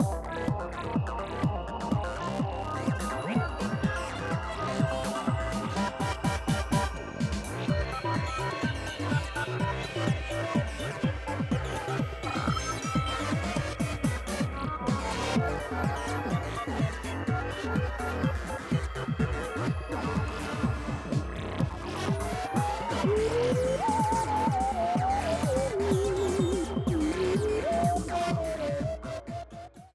Plays during battles in the parallel world